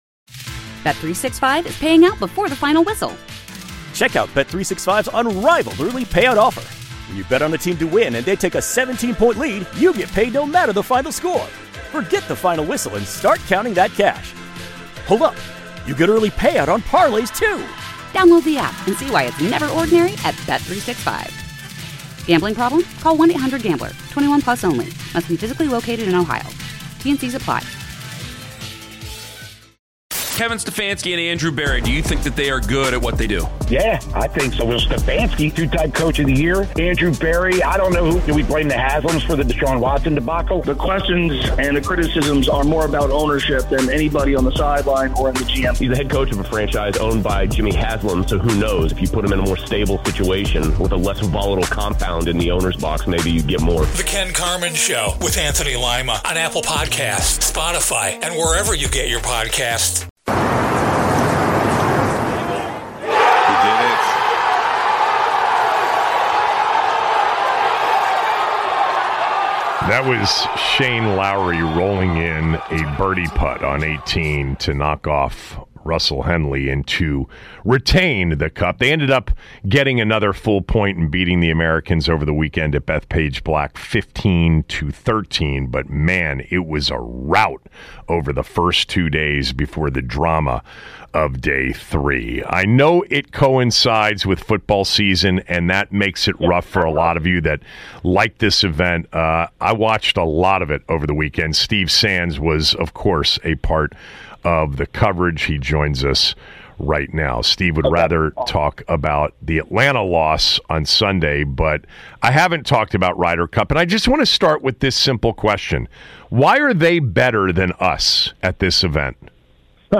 Best Interviews on 106.7 The Fan/Team 980: Sept. 29-Oct. 3, 2025